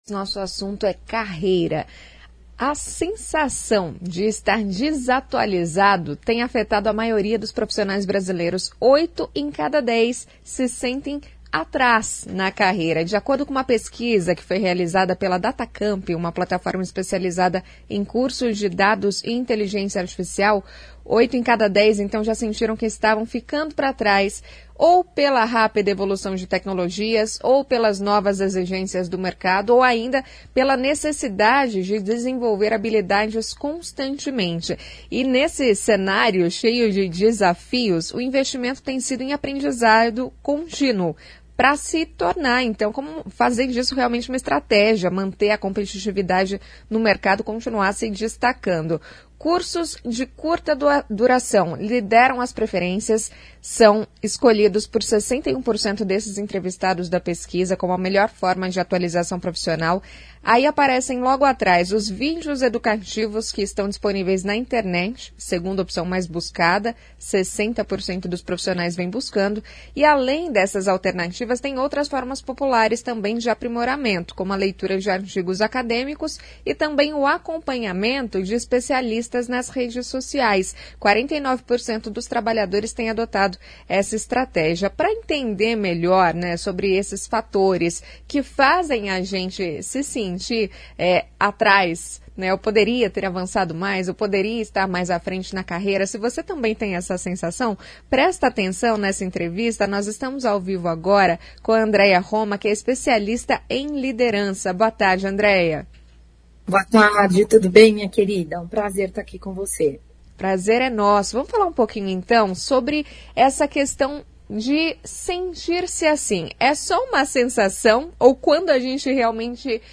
Em entrevista à CBN Maringá